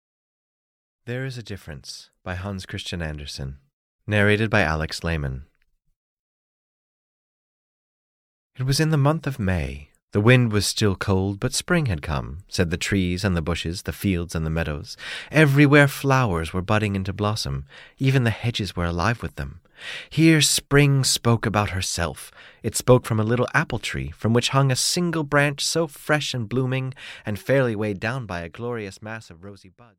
There is a Difference (EN) audiokniha
Ukázka z knihy